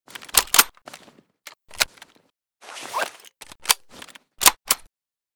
sv98_reload_empty.ogg